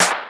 Snares
snr_74.wav